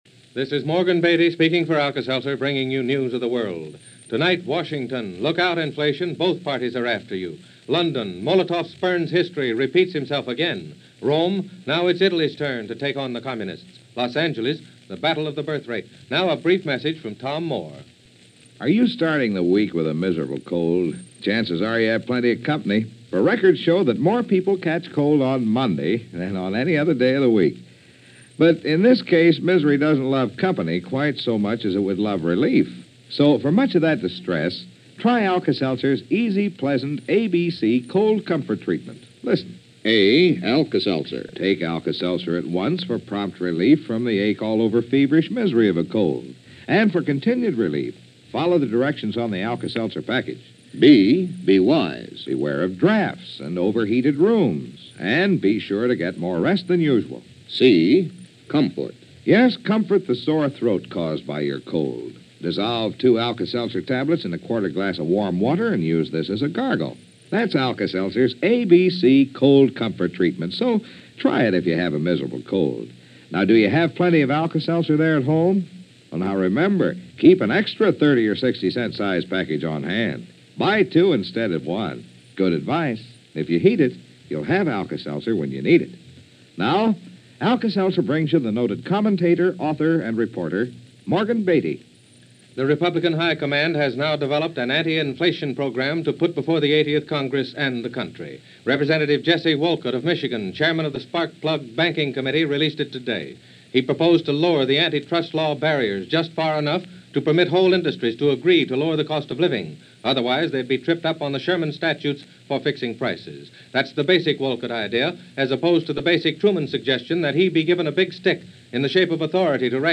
Politics And Inflation - Season Of Adjustment And Discontent - December 8, 1947 - as presented by NBC Radio News Of The World.